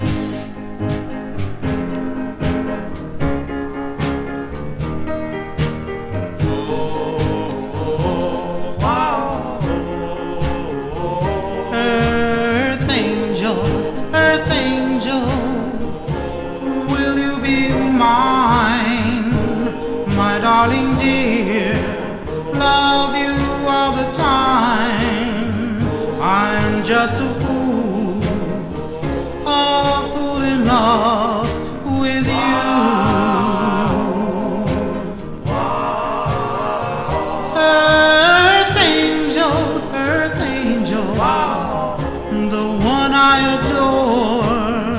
Rhythm & Blues, Doo Wop　USA　12inchレコード　33rpm　Mono